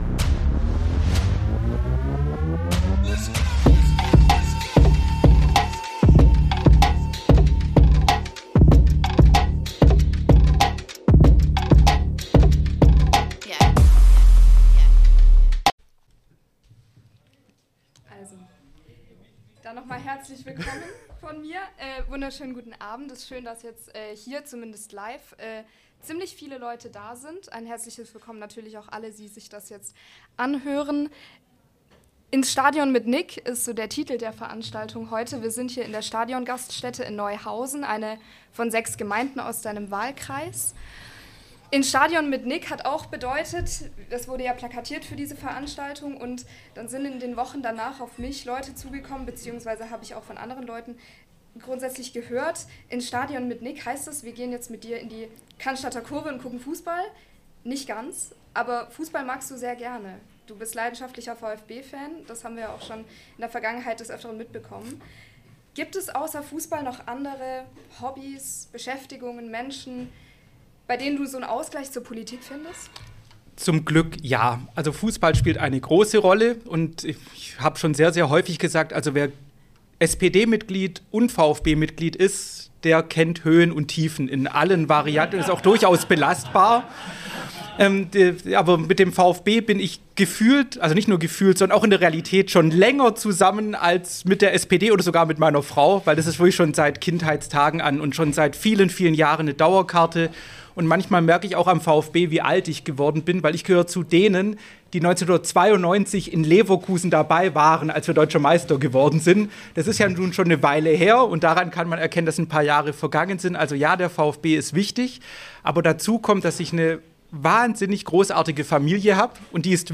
Diesmal melden wir uns mit einer besonderen Ausgabe: einem Live-Podcast aus dem Restaurant Stadion in Neuhausen auf den Fildern.